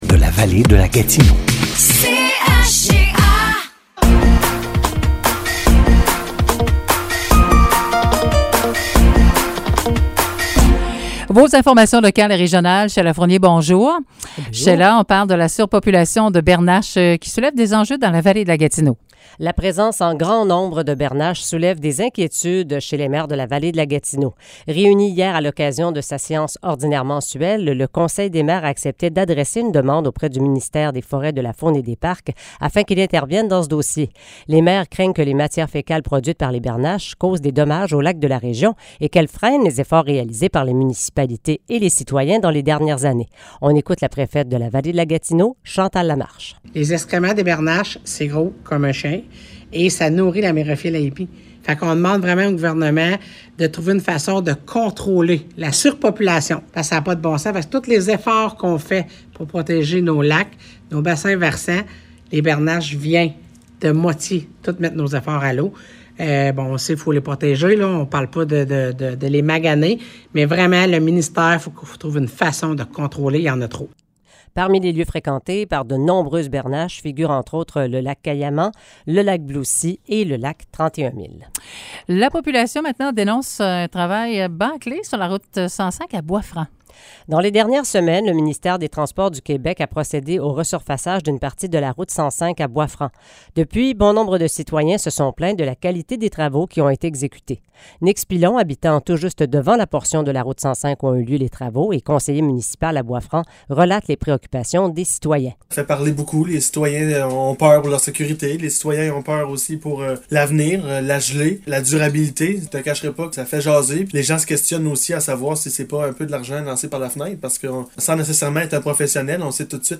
Nouvelles locales - 21 septembre 2022 - 10 h